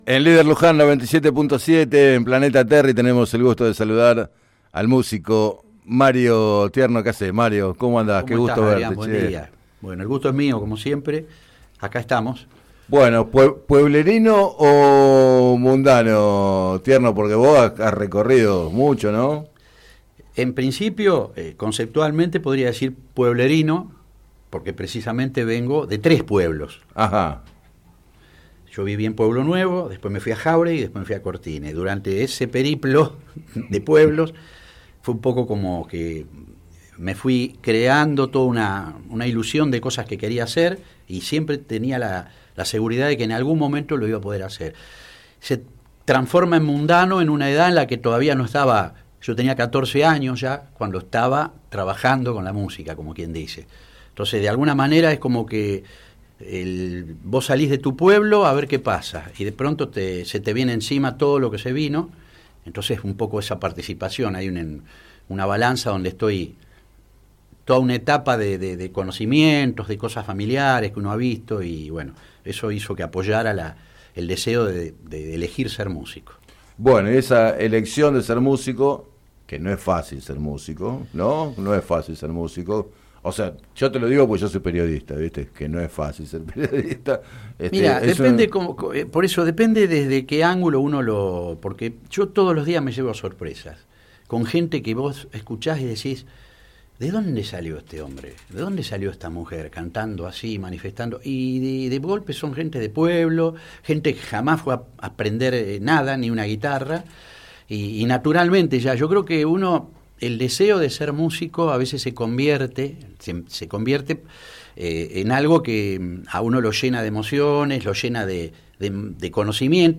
Entrevistado en el programa Planeta Terri